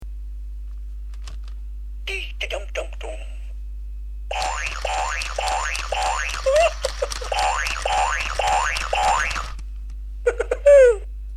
un son de mauvaise qualité mais quel bonheur!